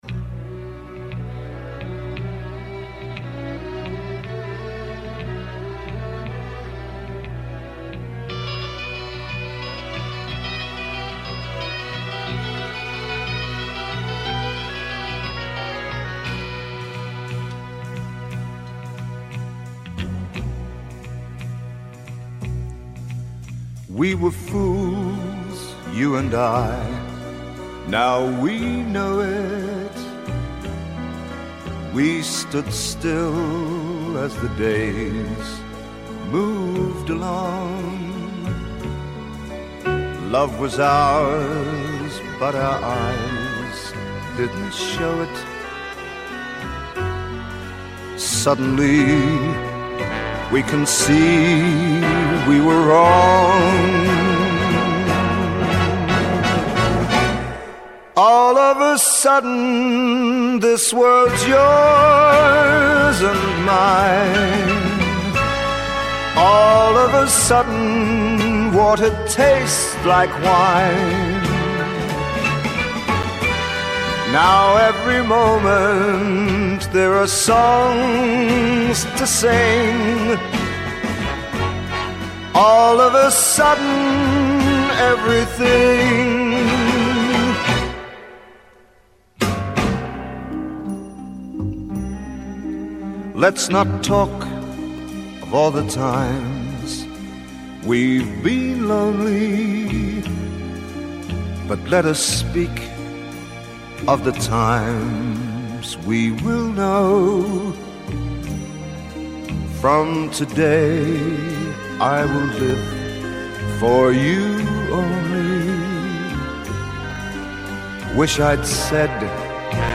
خواننده انگلیسی‌